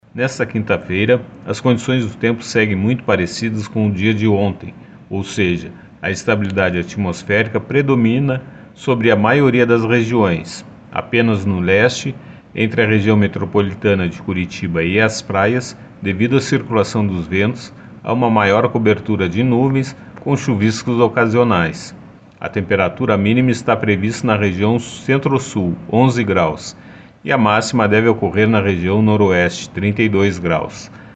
Previsão do tempo